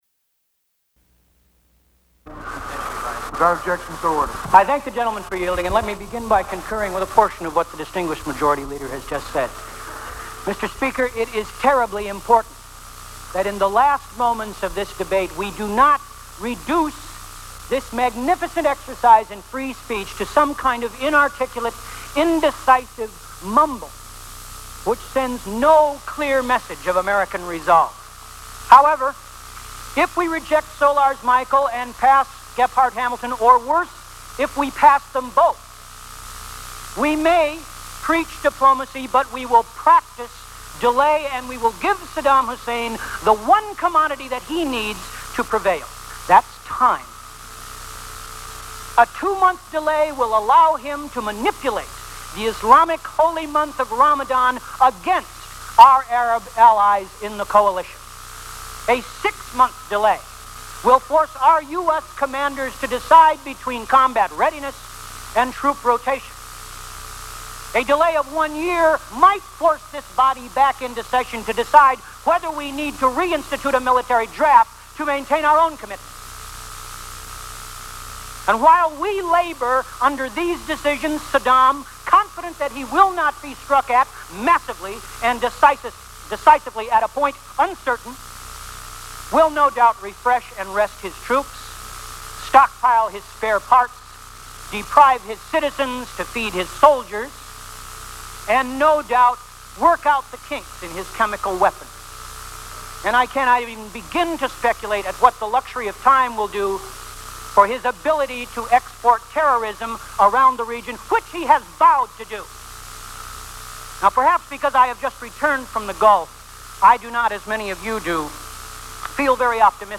James Baker answers questions following his meeting with Iraqi Foreign Secretary Tariq Aziz in Geneva
Broadcast on CNN, January 9, 1991.